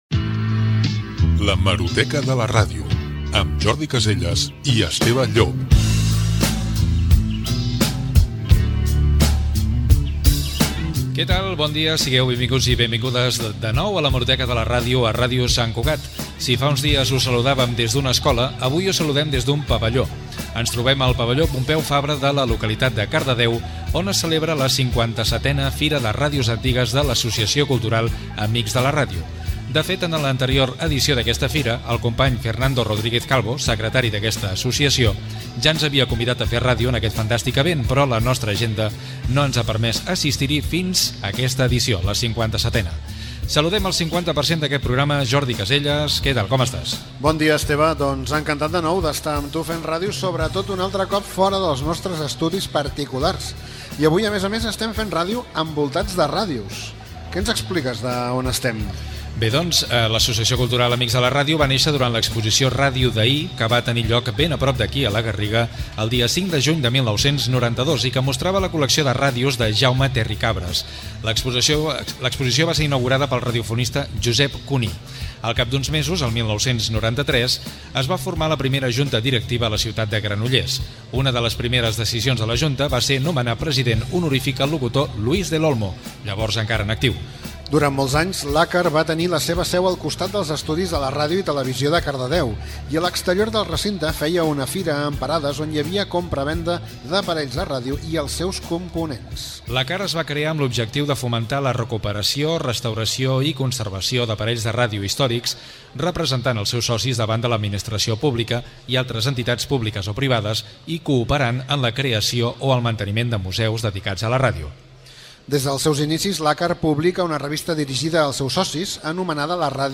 Espai enregistrat a la Fira de ràdios antigues de l'Associació Cultural Amics de la Ràdio (ACAR), a Cardedeu.
Divulgació